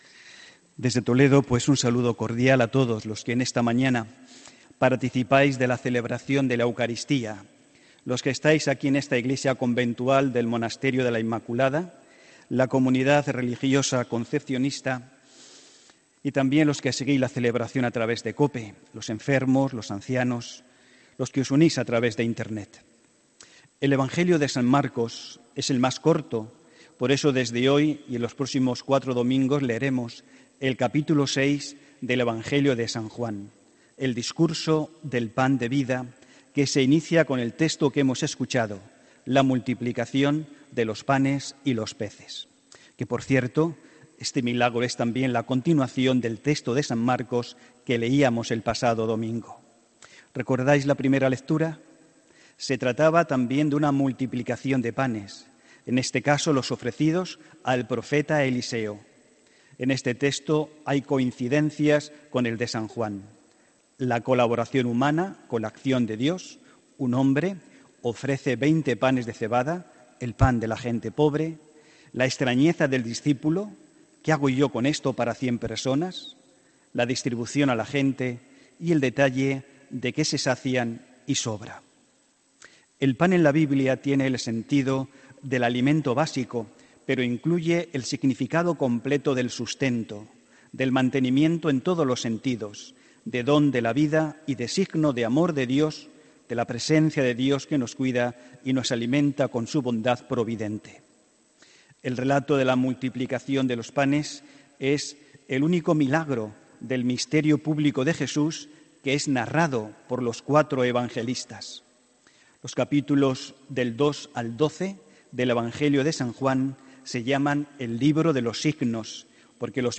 HOMILÍA 29 JULIO 2018